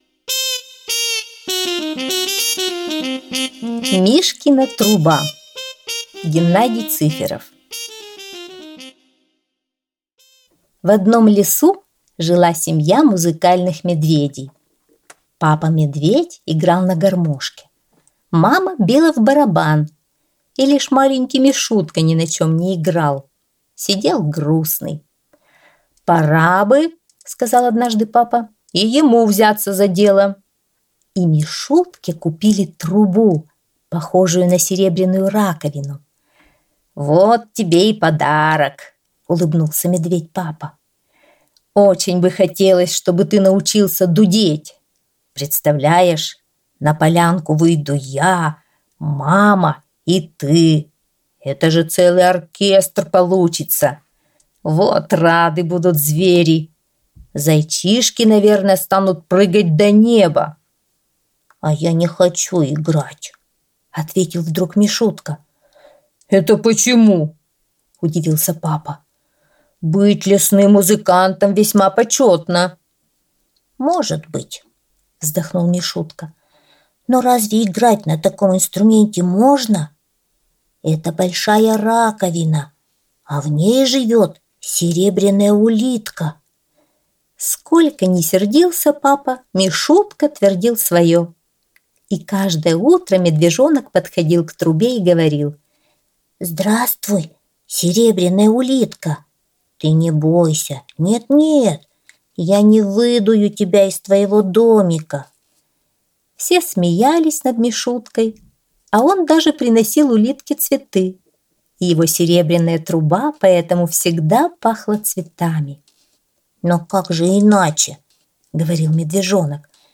Аудиосказка «Мишкина труба»